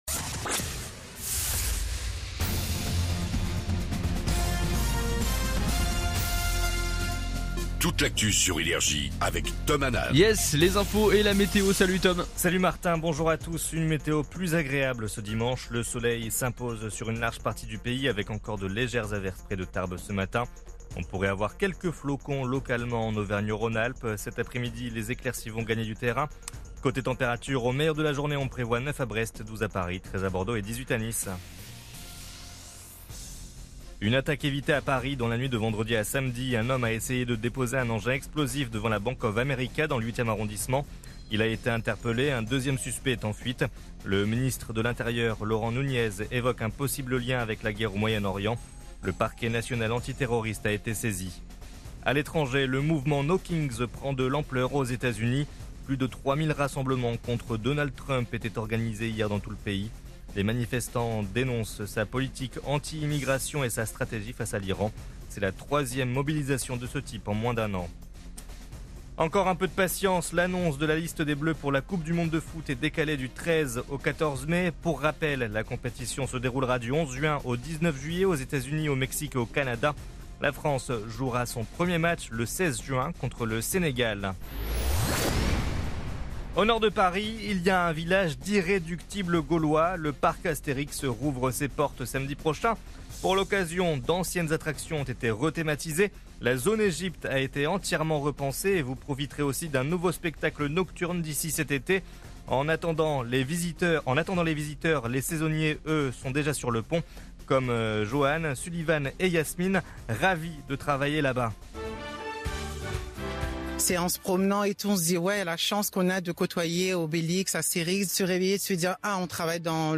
INFOS, METEO et TRAFIC